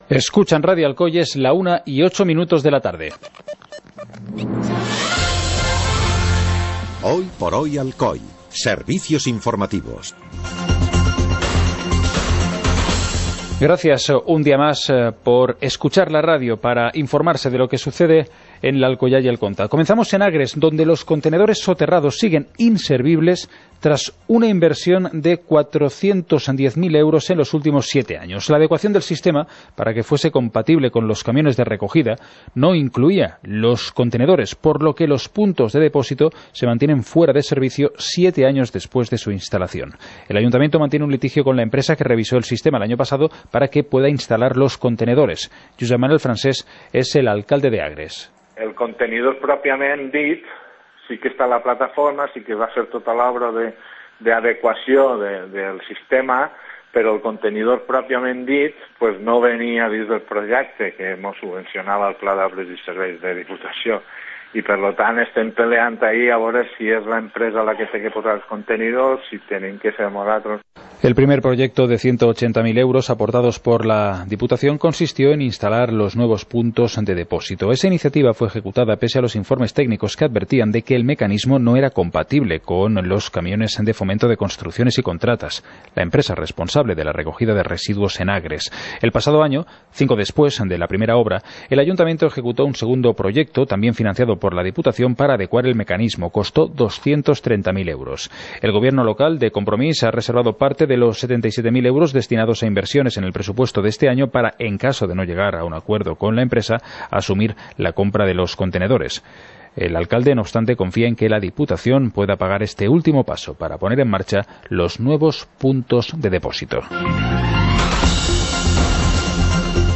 Informativo comarcal - martes, 29 de mayo de 2018